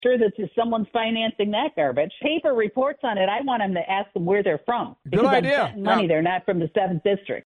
fischbach-full-interview.mp3